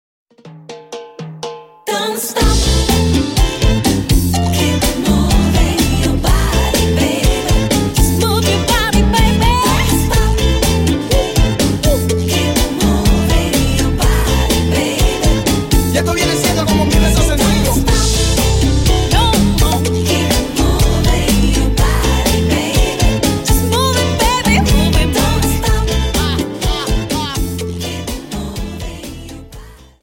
Dance: Cha Cha 31